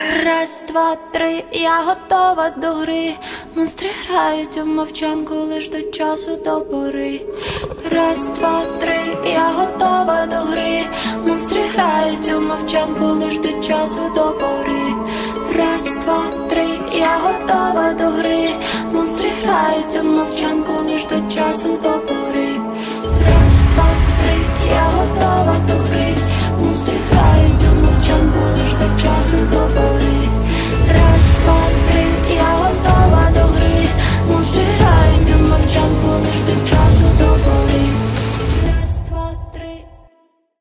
• Качество: 128, Stereo
поп
женский вокал
тревожные
устрашающие